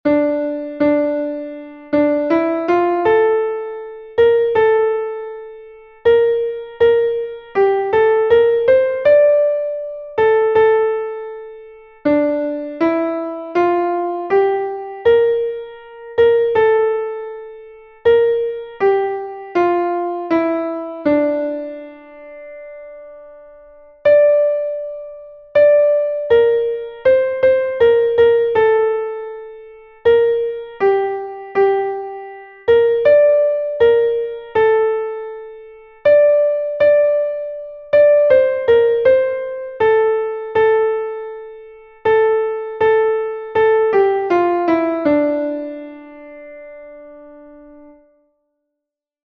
Melodic dictation